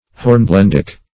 Search Result for " hornblendic" : The Collaborative International Dictionary of English v.0.48: Hornblendic \Horn*blend"ic\, a. Composed largely of hornblende; resembling or relating to hornblende.